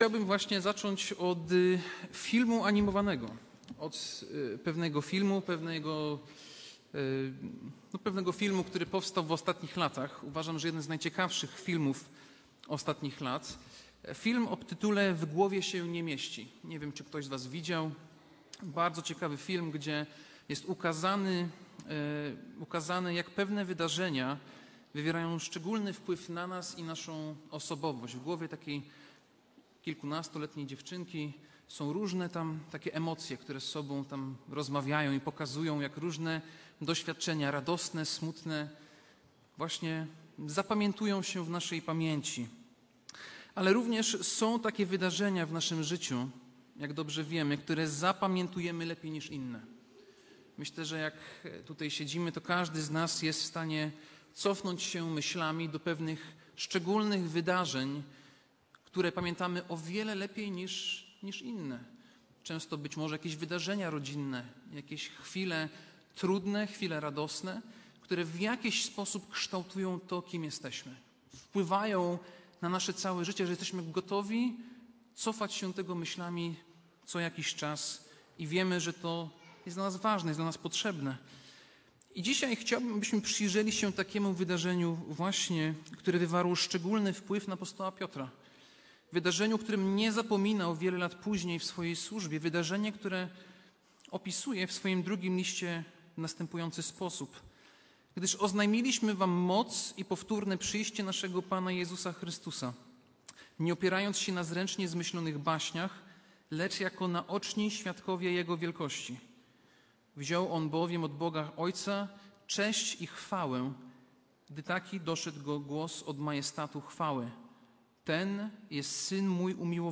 Kazanie